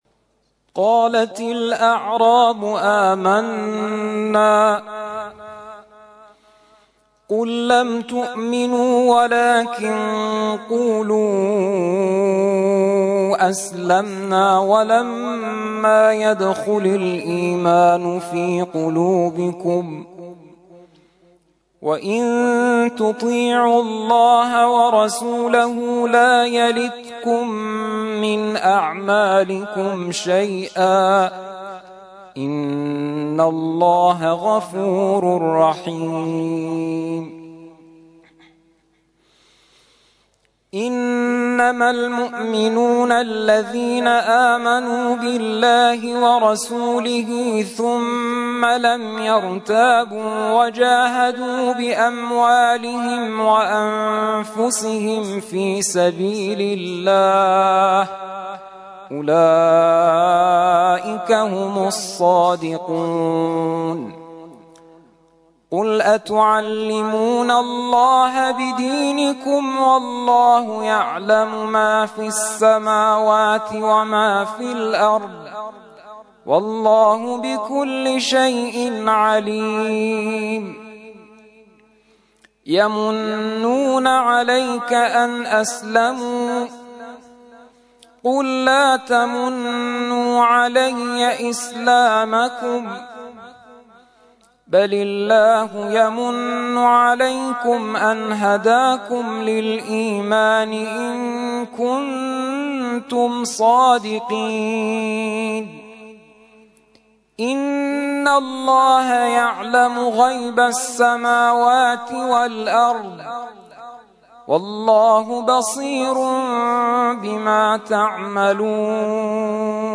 ترتیل خوانی جزء ۲۶ قرآن کریم در سال ۱۳۹۹